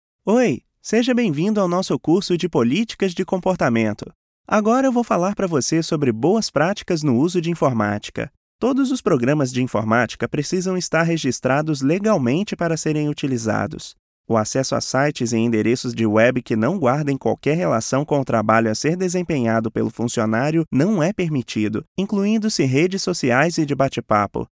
Male
Yng Adult (18-29), Adult (30-50)
Natural, guy next door, professorial, professional, corporate, character's voice, narrator, educative, explainer, inspirational, smooth.
E-Learning
Guy Next Door Style Teacher